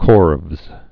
(kôrvz)